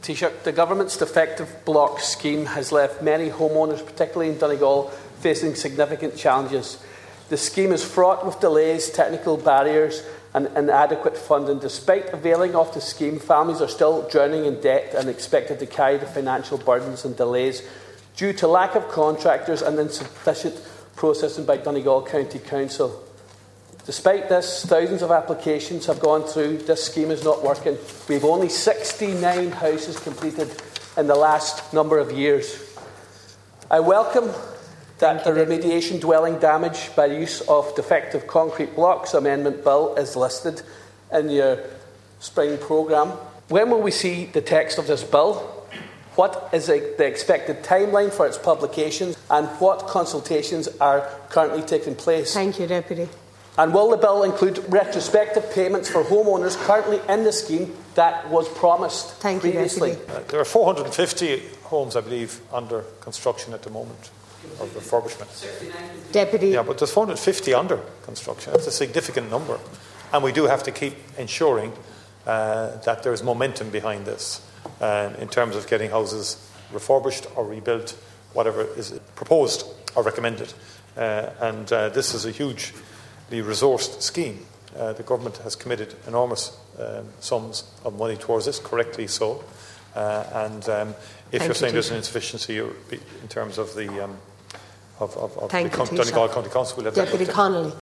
Ward tells Dail the DCB scheme is not working
The Taoiseach said if there are issues on the council’s side, they must be addressed, but defended the progress of the scheme so far.